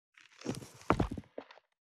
442物を置く,バックを置く,荷物を置く,トン,コト,ドサ,ストン,ガチャ,ポン,タン,スッ,ゴト,カチャ,
効果音室内物を置く